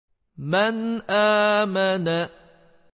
Tenvin veya nûn-i sakin'den sonra ızhar haflerinden biri gelirse, ızhar harfleriyle nun sesinin arası açılarak okunur.